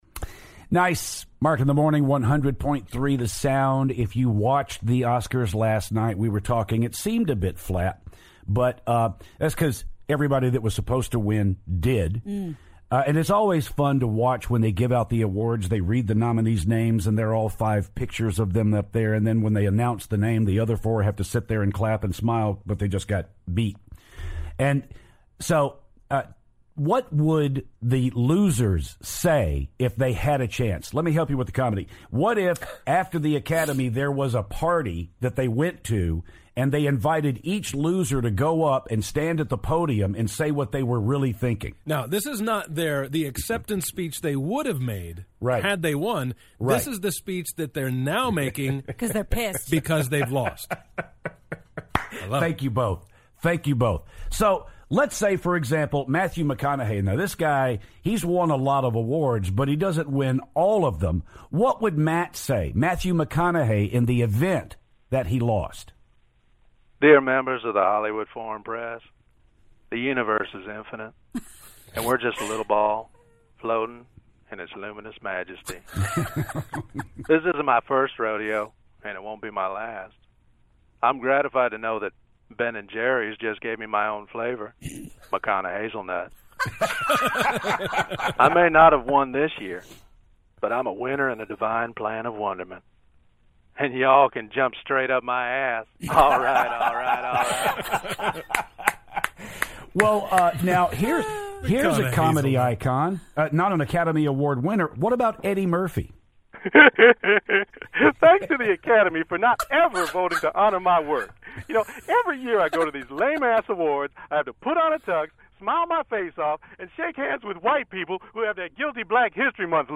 Losing speeches from the likes of Mathew McConaughey and Eddie Murphy.